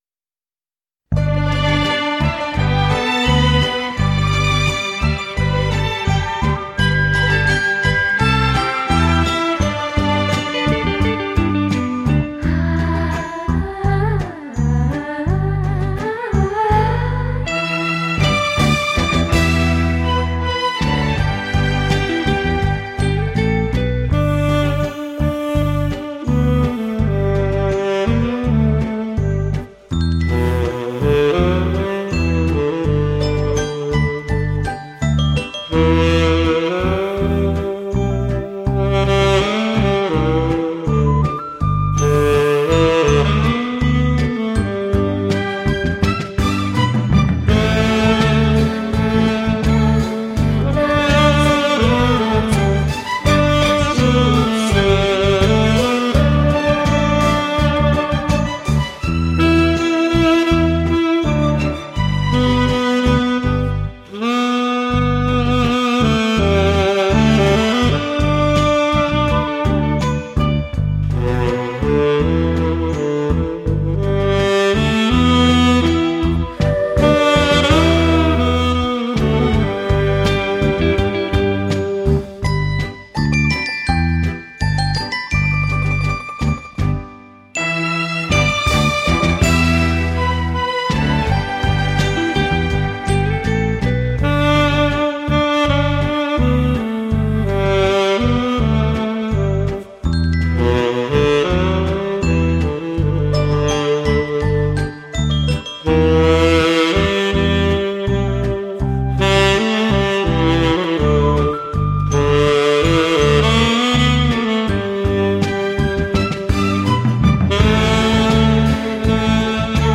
サックス奏者
哀愁のムード・サックス歌謡編